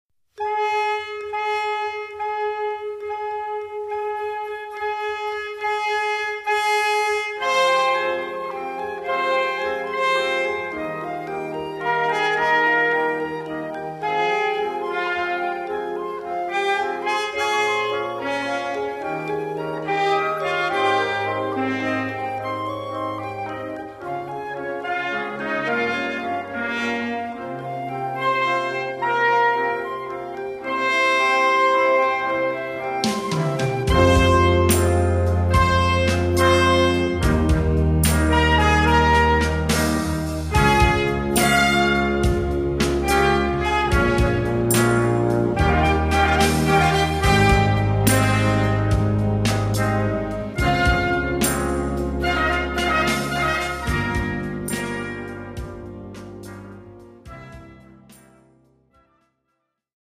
Gattung: Konzertante Blasmusik
A4 Besetzung: Blasorchester Zu hören auf